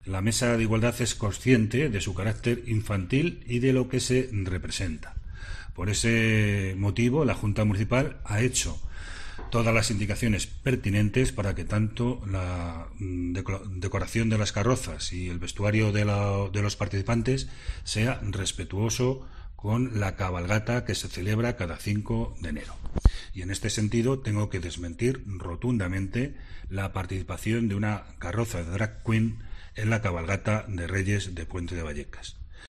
Concejal presidente del distrito Puente de Vallecas, Paco Pérez sobre la cabalgata de Reyes